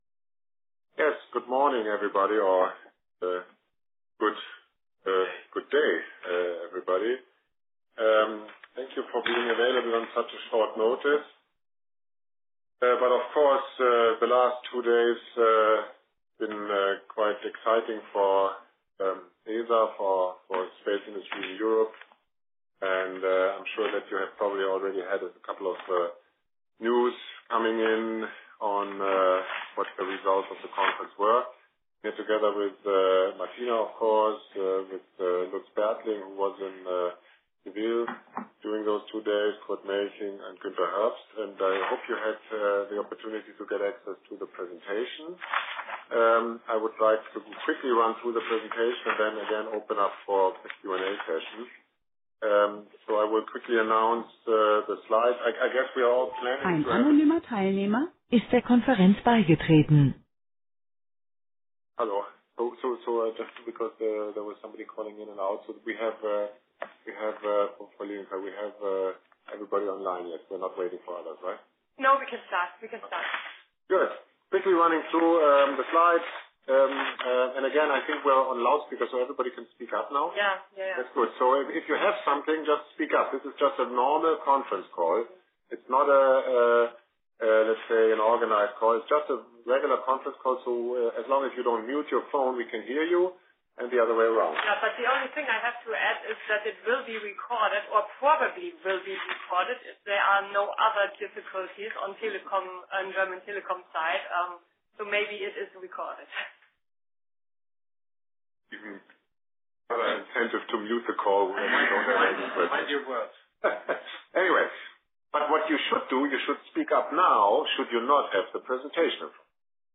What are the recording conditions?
Recording of the analysts Conference Call of the results of ESA ministerial council.